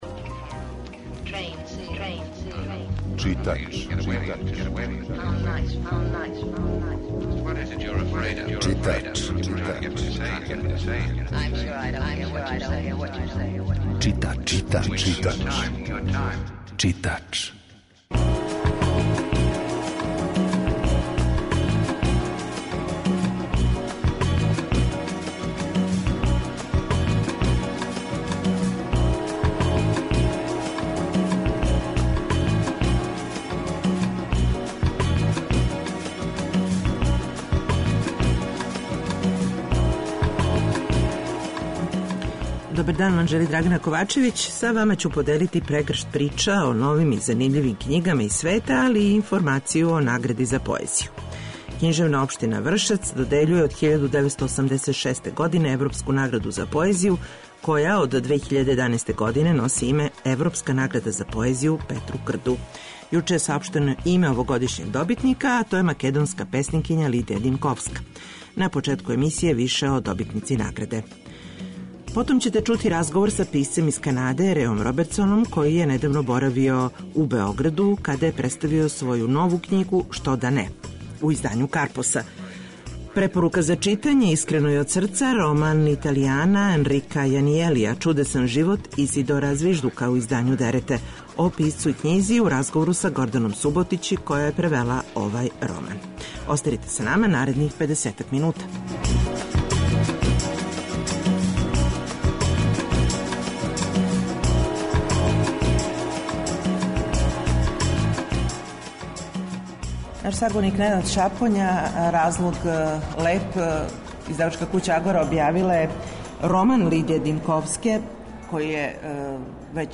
Аудио подкаст Радио Београд 2